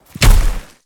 Sfx_creature_snowstalker_run_os_06.ogg